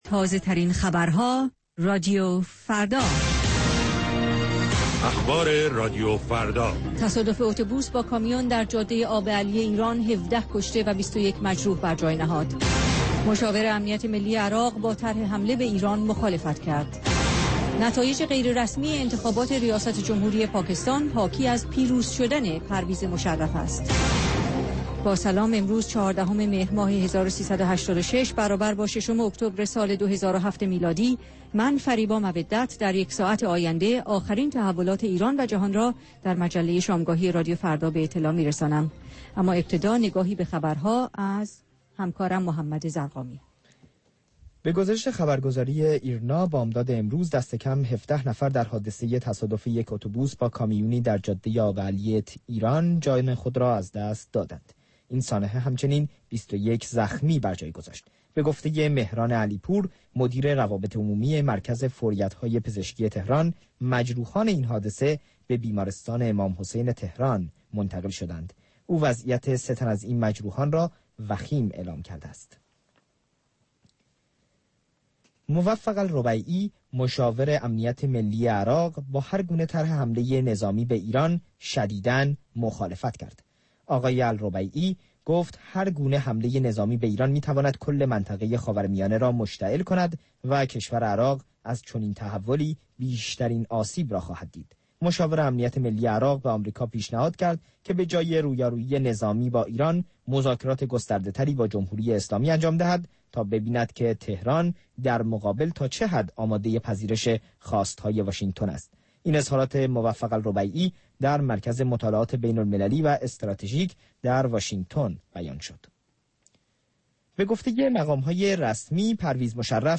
مجموعه ای متنوع از آنچه در طول روز در سراسر جهان اتفاق افناده است. در نیم ساعات مجله شامگاهی رادیو فردا، آخرین خبرها و تازه ترین گزارش های تهیه کنندگان این رادیو فردا پخش خواهند شد.